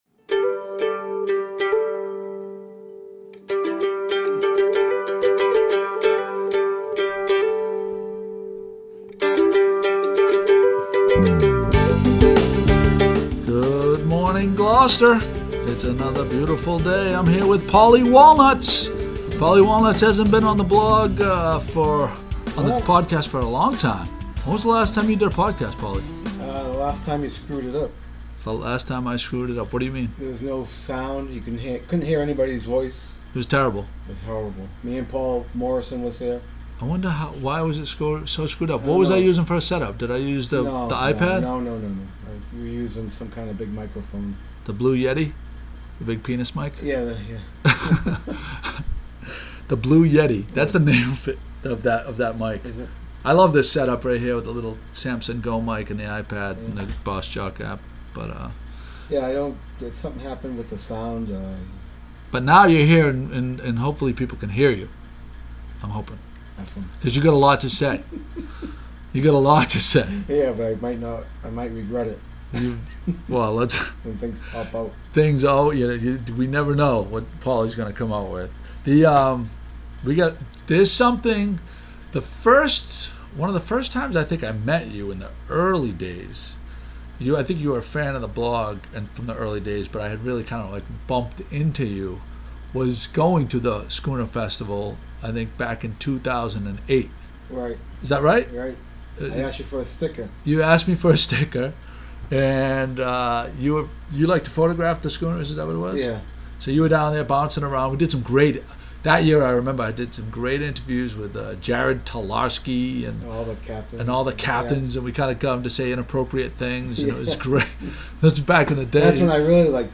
Taping with The Blue Yeti